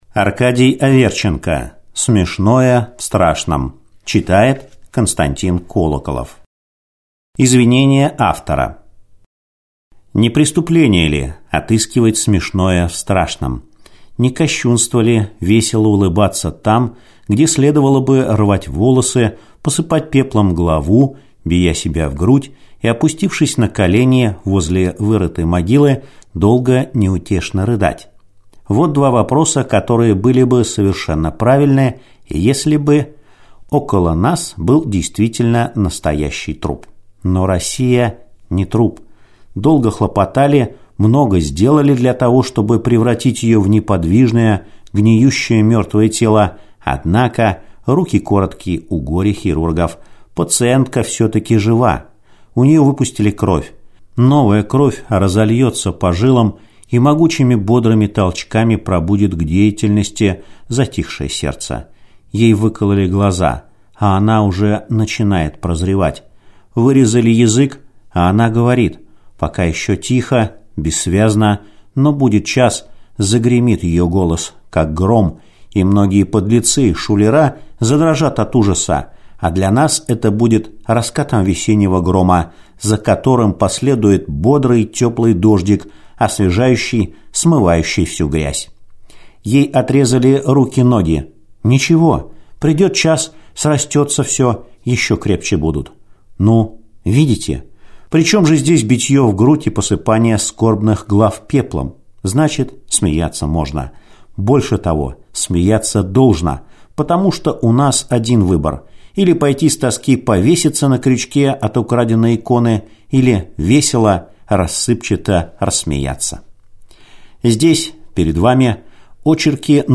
Аудиокнига Смешное в страшном | Библиотека аудиокниг